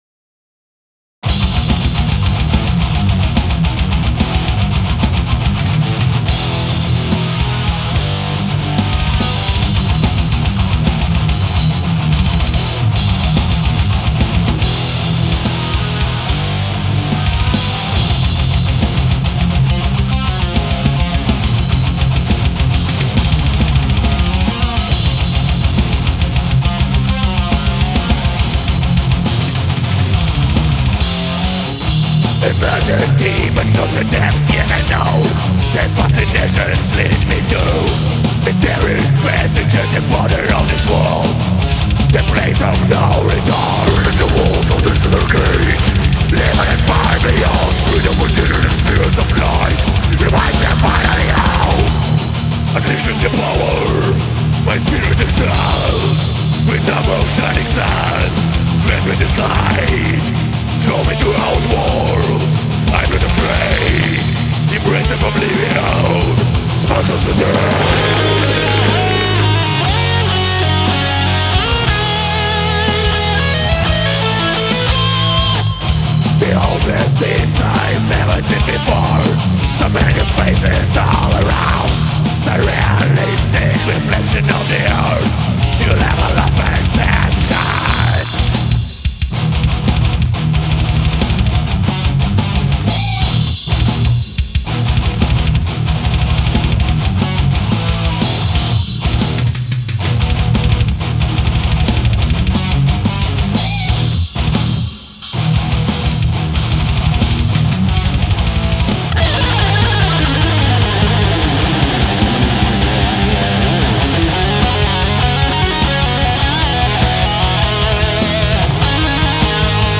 довольно крепкий релиз :oops:
Судя по отрывкам, блэка здесь минимум.